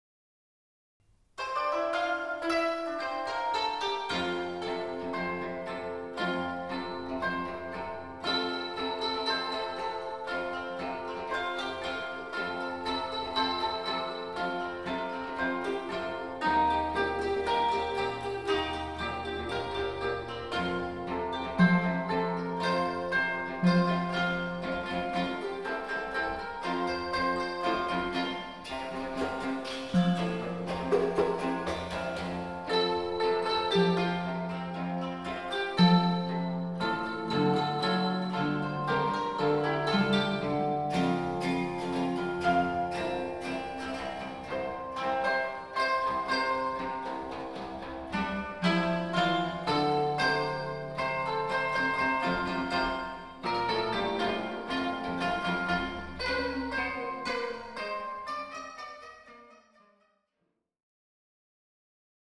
箏1
箏2
十七絃